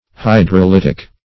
Hydrolytic \Hy`dro*lyt"ic\, a. [Hydro-, 1 + Gr.